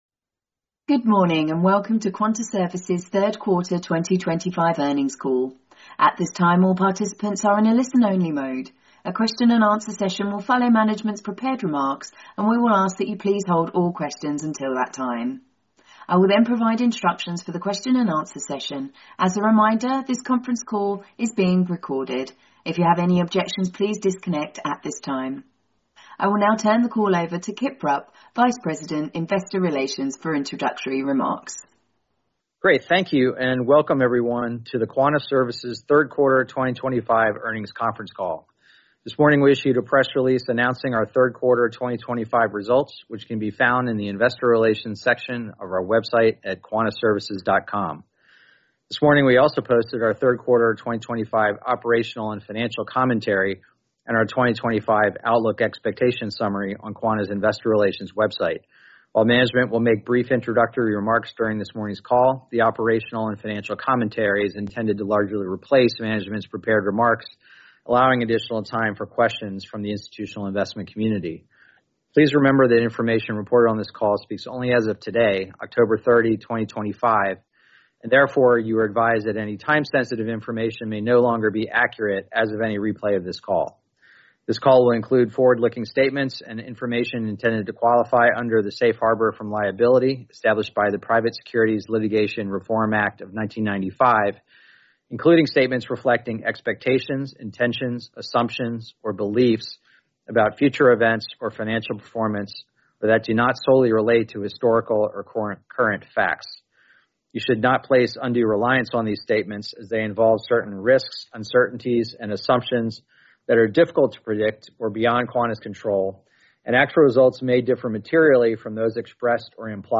PWR+3Q25+Earnings+Call.mp3